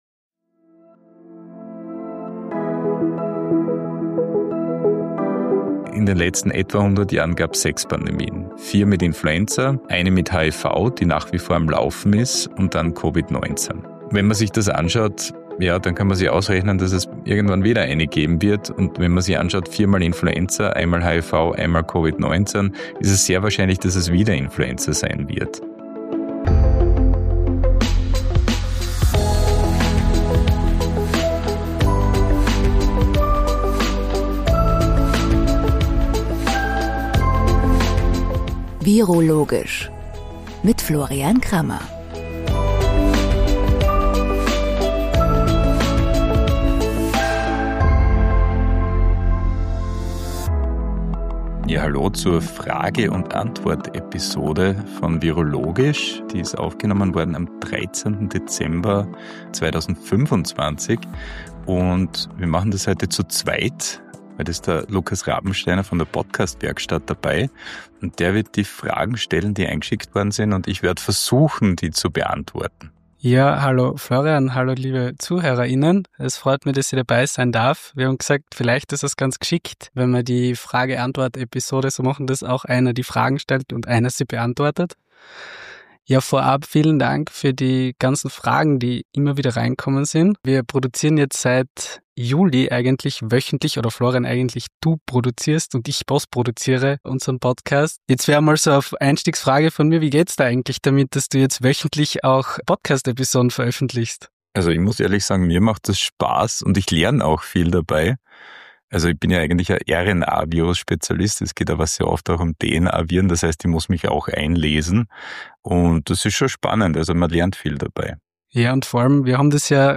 #26 – Q&A: Eure Fragen im Fokus ~ viroLOGISCH Podcast